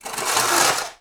SPADE_Scrape_Asphalt_RR4_mono.wav